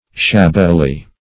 Shabbily \Shab"bi*ly\, adv.